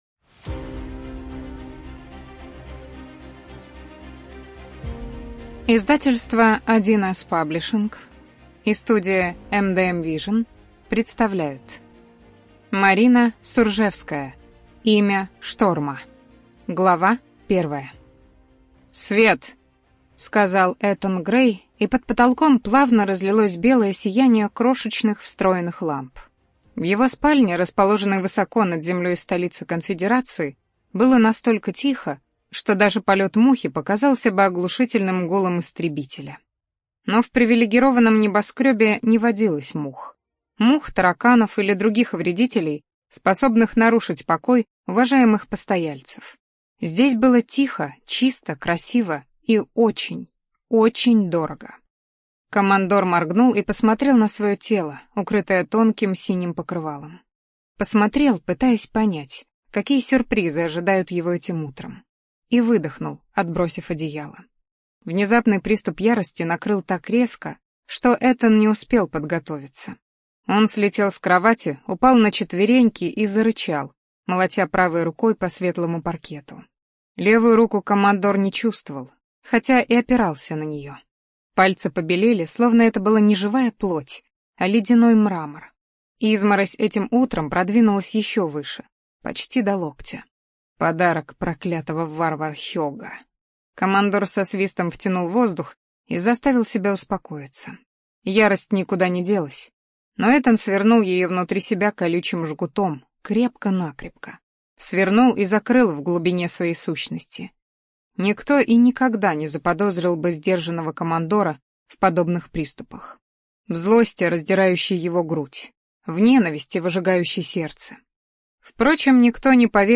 ЖанрФэнтези
Студия звукозаписи1С-Паблишинг